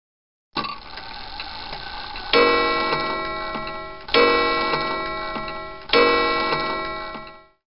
Standuhr
Beim folgenden Geräusch handelt es sich um eine Standuhr, welche häufig in bäuerlichen Stuben anzutreffen ist. Bei dieser Aufzeichnung ist das Schlagen der Uhr zu jeder vollen Stunde zu hören. Dabei schlägt ein kleiner Hammer auf ein spiralförmiges Metallband und erzeugt so diesen charakteristischen Klang.
standuhr